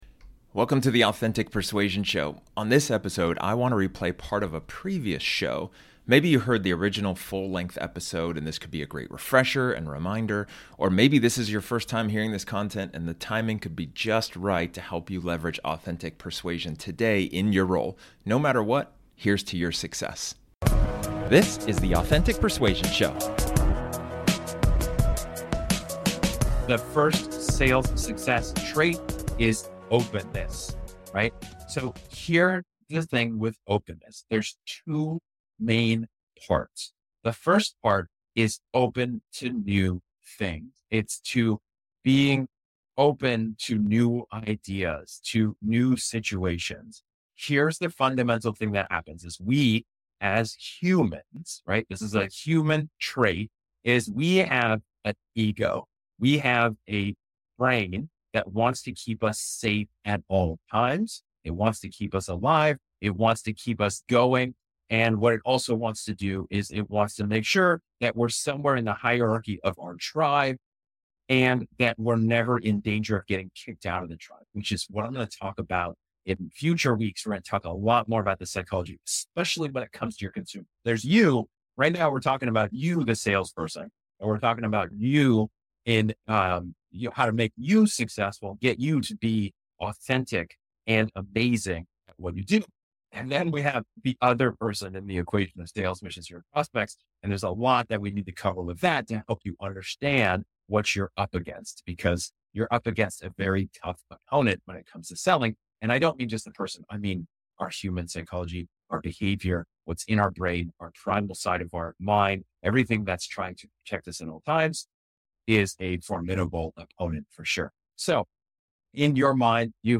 This episode is an excerpt from one of my training sessions where I talk about one of the 5 Sales Success Traits.